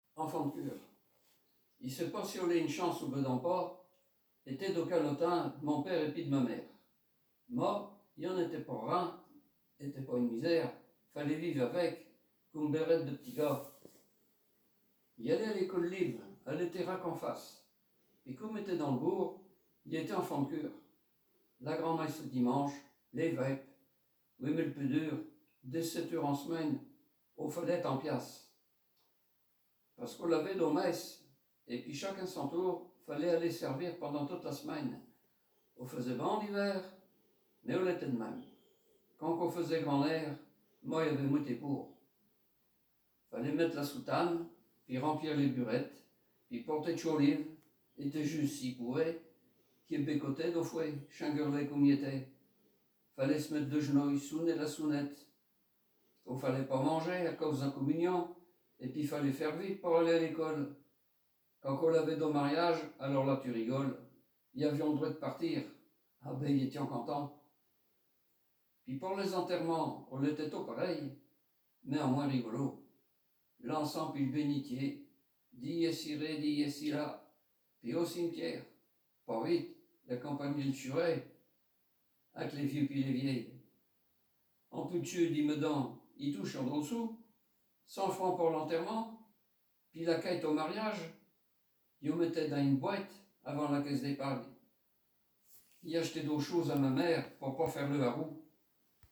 Poésies en patois